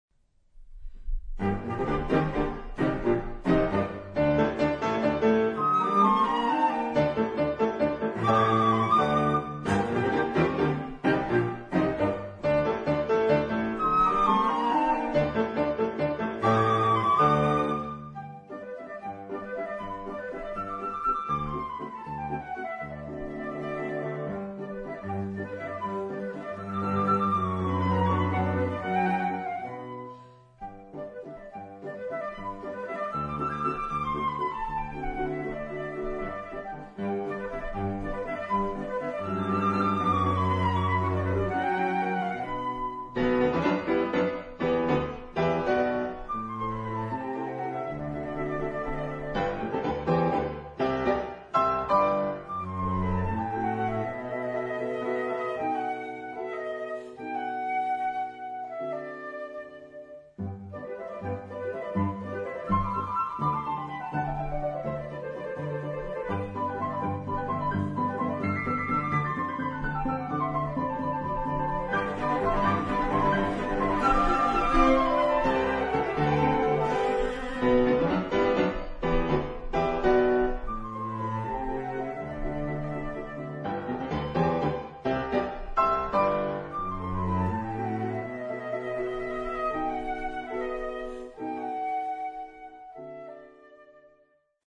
Allegro Moderato
Trio pour flûte violoncelle piano
En Sol Mineur